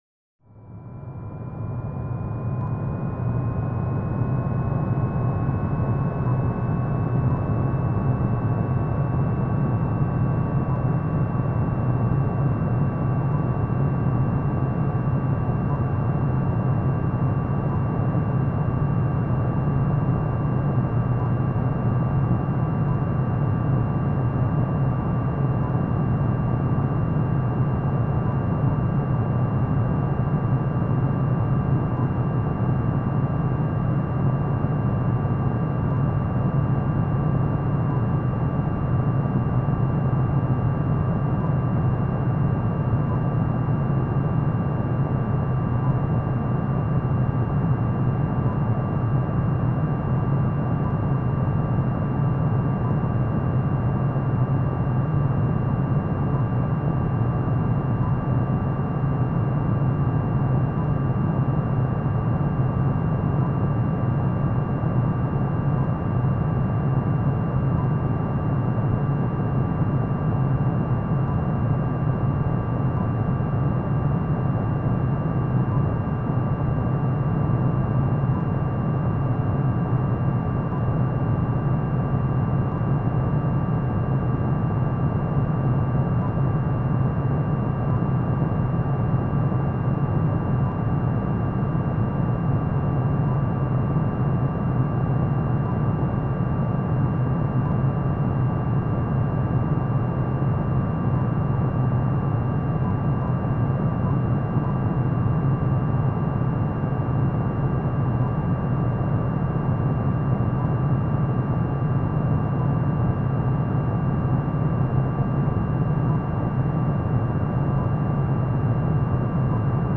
Sound Design, Spaceship inflight (Sound FX)
Spaceship Inflight Ambiance, hovering and coming into land. Airy drone, rush, jet sounds, power down. Good for any turbine, fan or engine power and power off. Science Fiction Sound Effects, Spaceship landings
SoundDesign_SpaceShip_Inflight_Land_plip.mp3